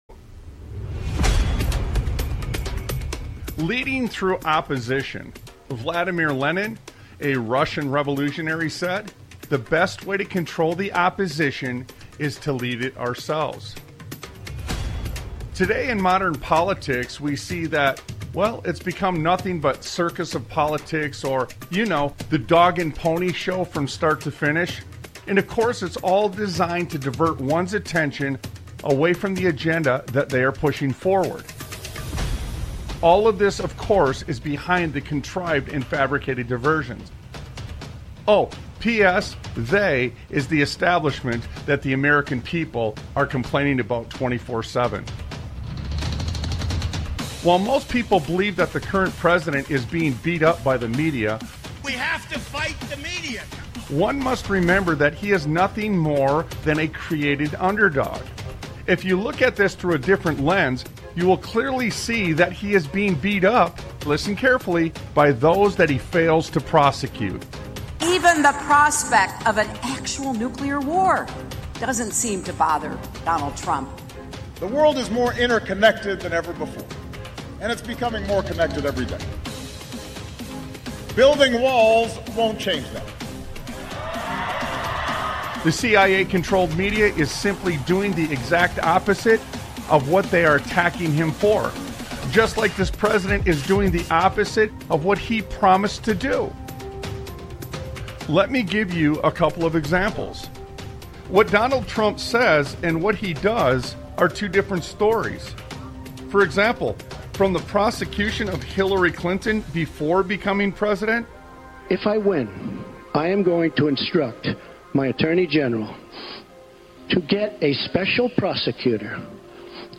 Sons of Liberty Radio Talk Show